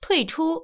ivr-to_log_out.wav